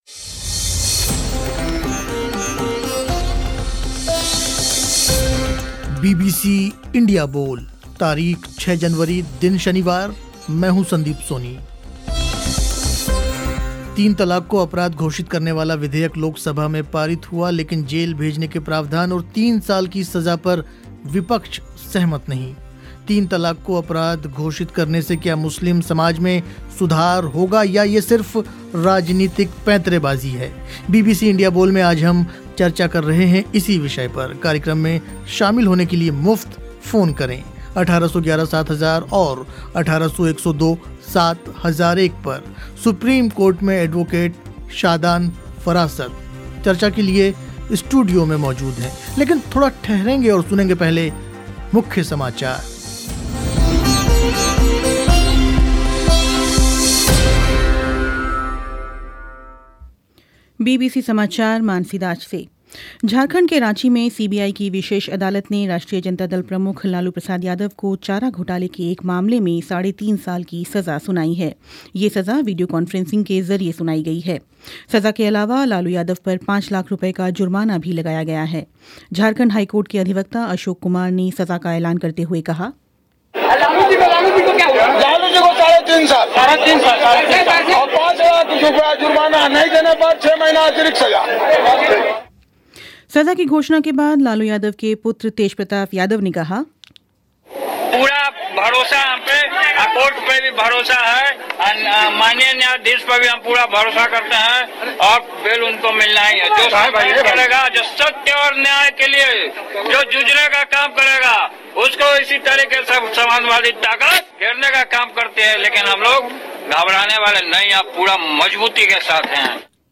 चर्चा के लिए स्टूडियो में मौजूद थे